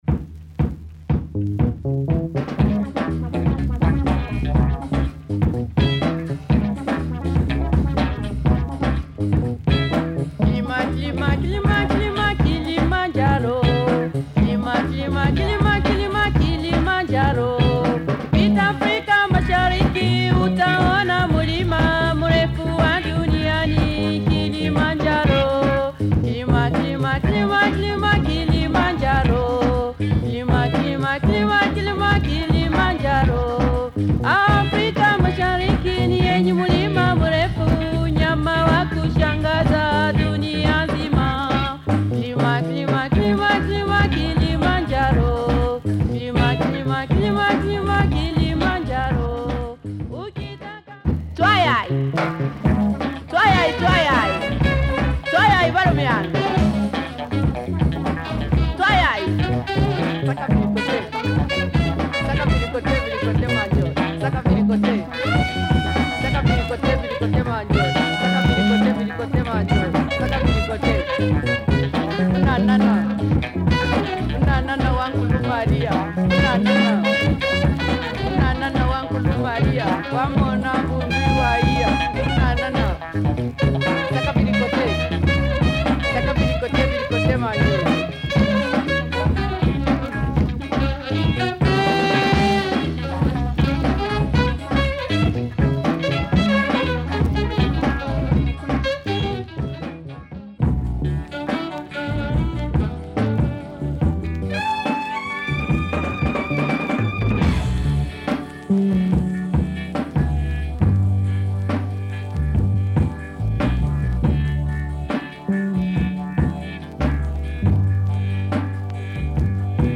Nice Kenyan record !
the raw afro
the soulful
the discoid
the reggae